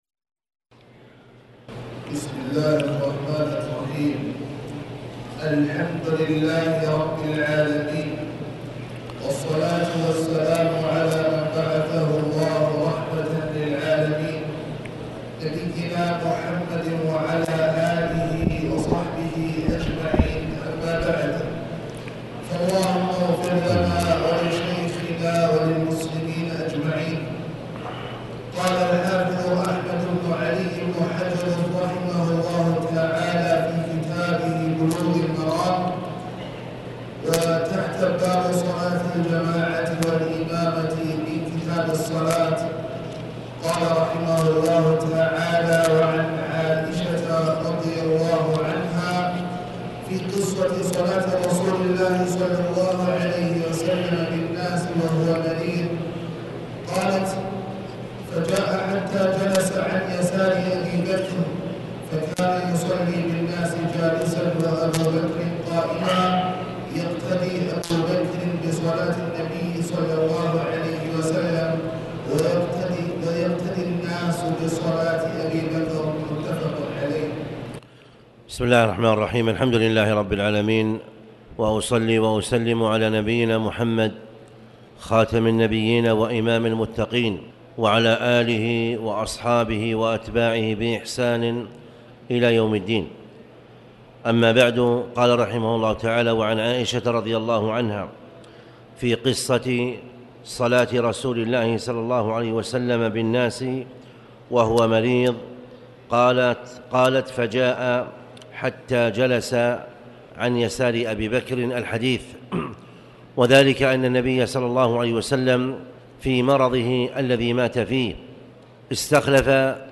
تاريخ النشر ٢٦ صفر ١٤٣٩ هـ المكان: المسجد الحرام الشيخ